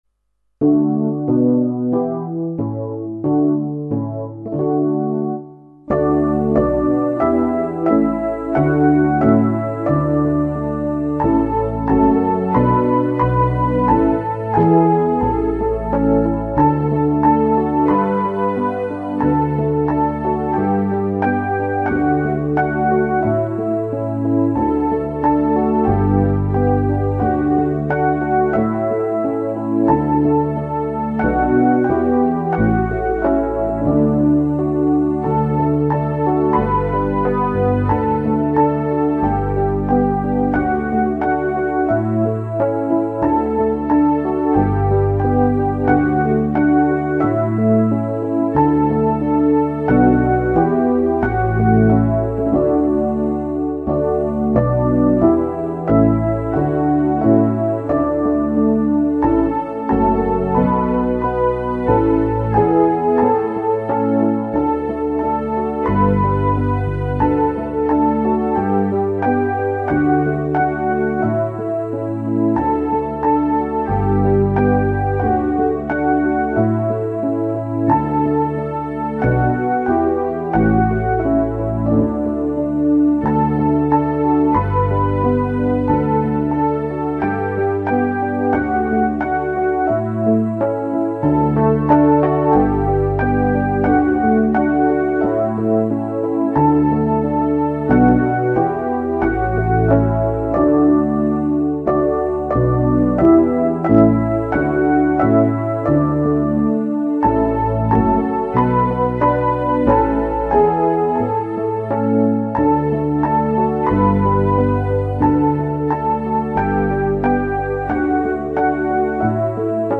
W załączeniu przesyłam kilka propozycji wraz z podkładami muzycznymi.